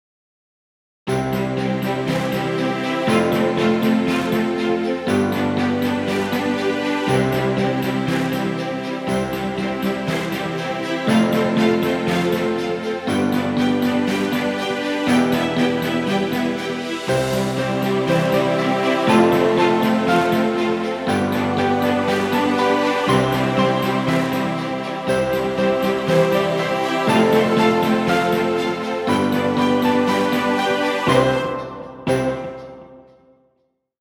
Holiday and Christmas music. Festive positive track.